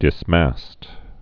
(dĭs-măst)